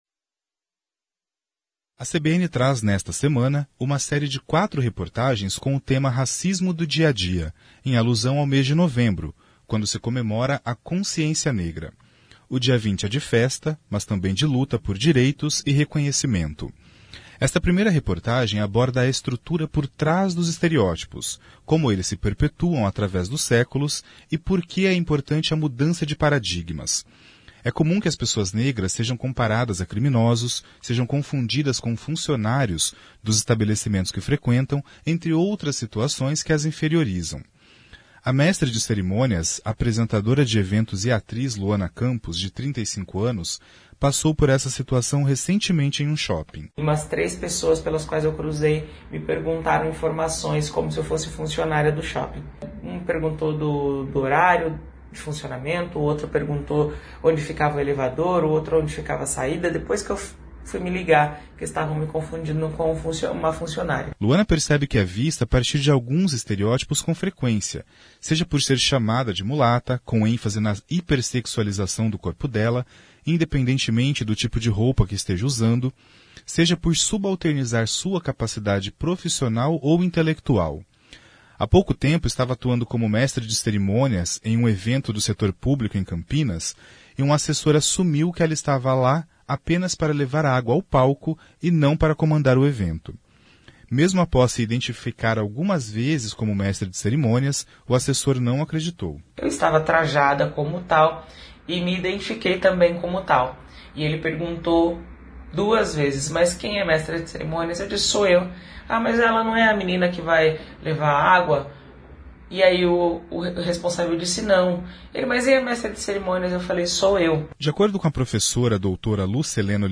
Esta primeira reportagem aborda a estrutura por trás dos estereótipos, como eles se perpetuam através dos séculos e por que é importante a mudança de paradigmas.